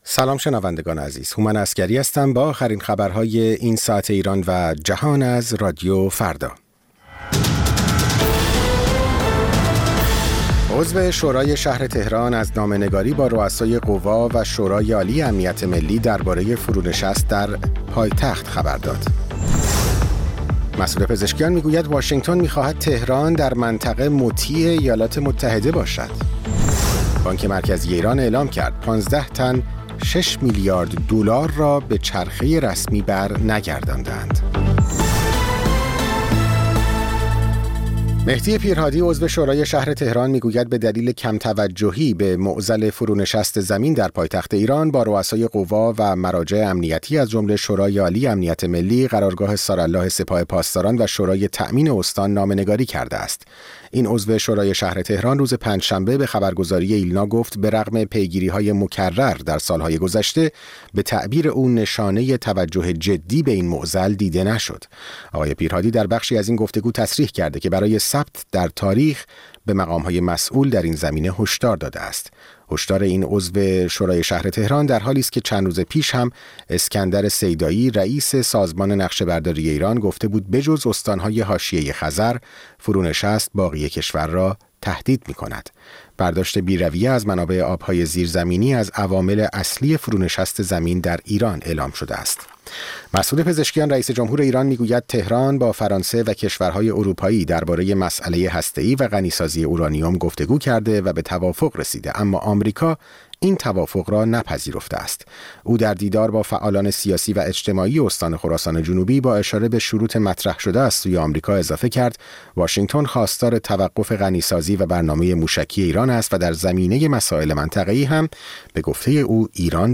سرخط خبرها ۸:۰۰